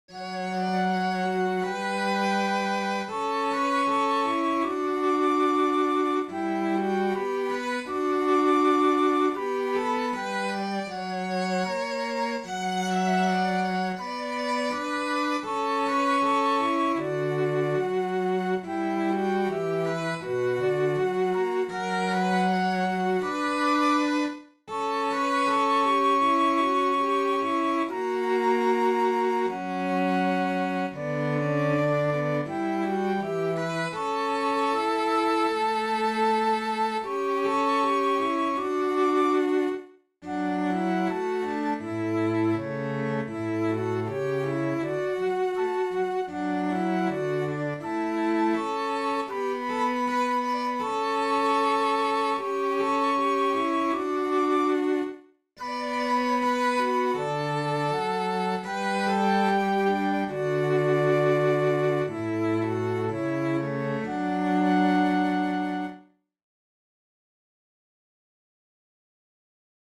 Talitintti laulaa
Talitintti-laulaa.mp3